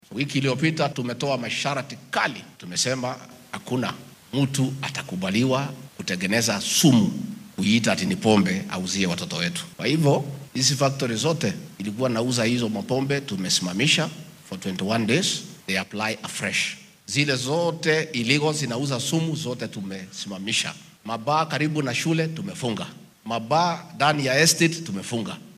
Arrimahan ayuu saaka ka sheegay munaasabad kaniiseed oo uu uga qayb galay deegaanka Roysambu ee ismaamulka Nairobi.